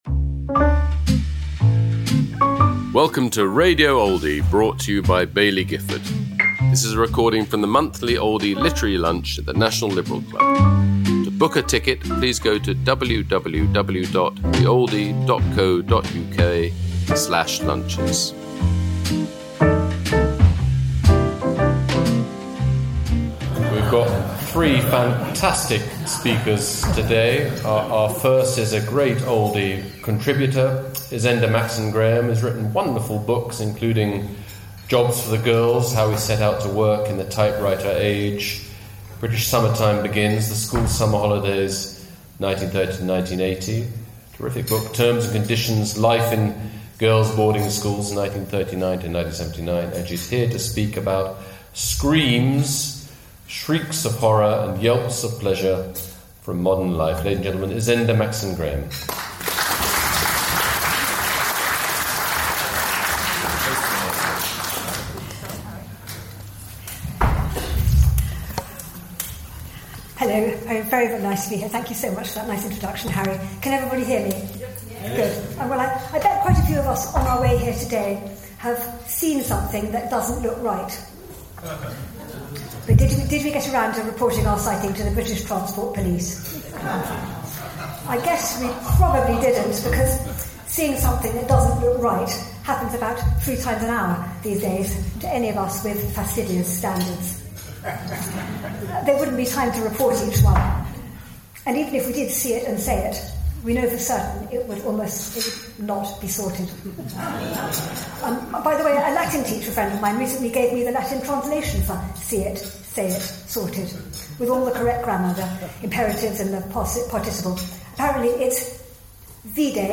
at the Oldie Literary Lunch, held at London’s National Liberal Club, on November 4th 2025.